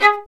Index of /90_sSampleCDs/Roland - String Master Series/STR_Violin 2&3vb/STR_Vln2 % + dyn